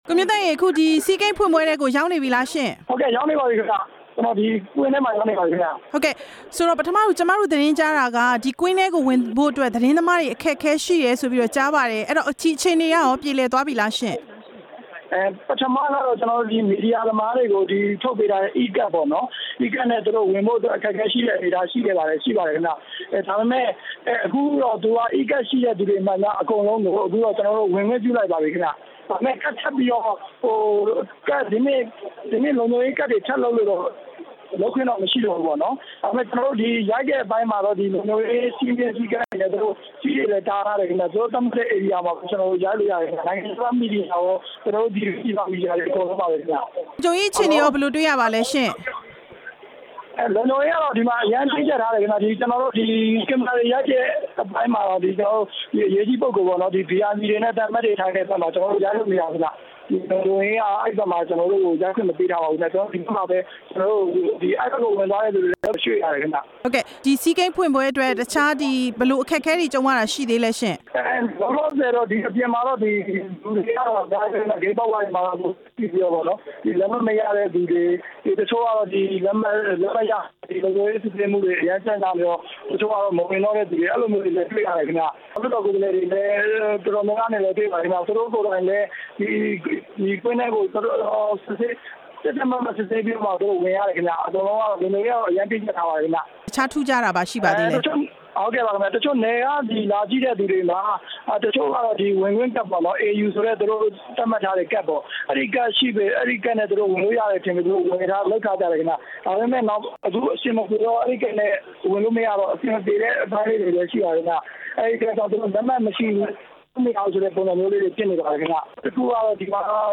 ဆီးဂိမ်း အားကစားဖွင့်ပွဲအကြောင်း မေးမြန်းချက်